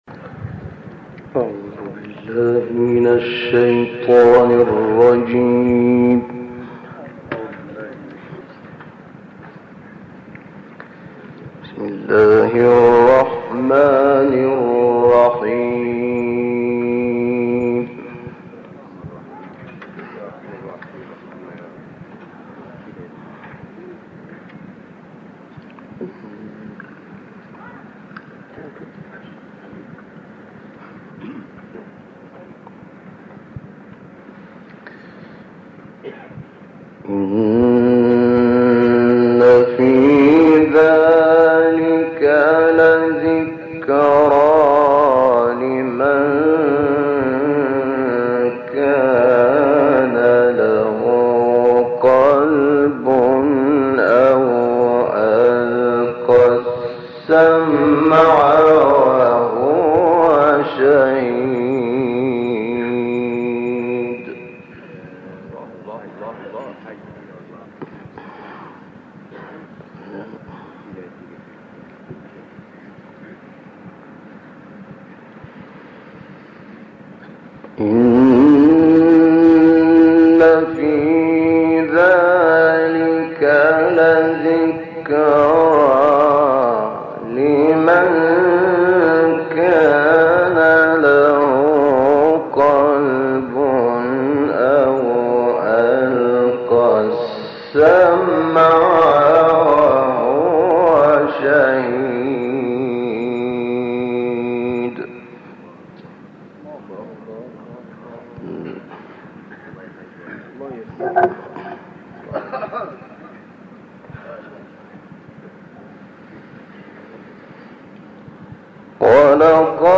تلاوتی زیبا از استاد شیخ شحات محمد انور قاری مشهور مصری که سوره های قاف ، بلد ، عادیات و تکاثر را شامل می شود.